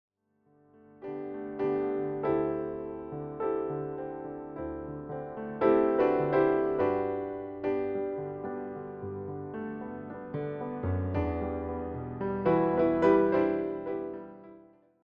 a consistent, relaxed romantic mood